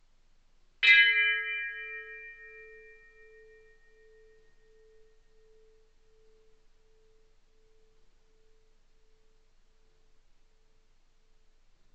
Bell 08
bell bing brass ding sound effect free sound royalty free Sound Effects